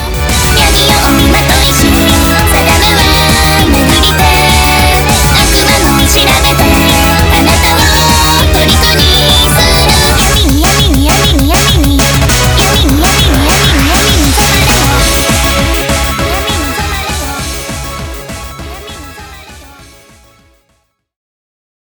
電波ソング